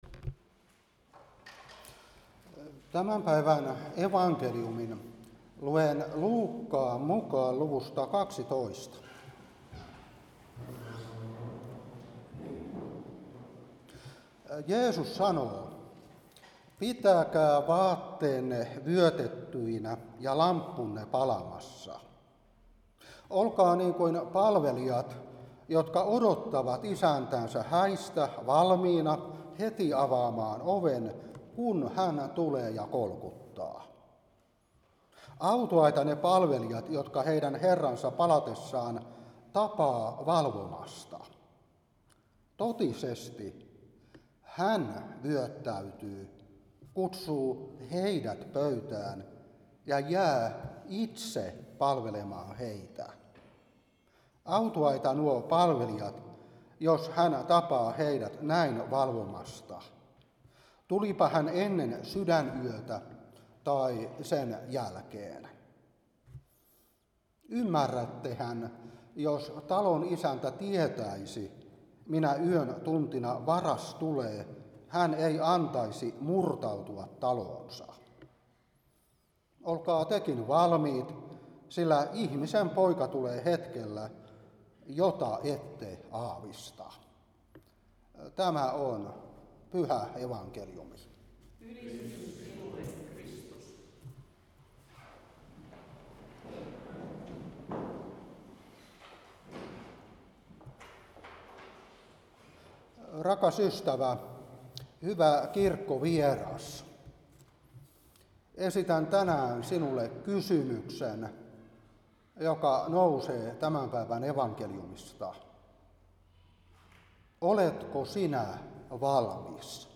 Saarna 2025-12. Luuk.12:35-40.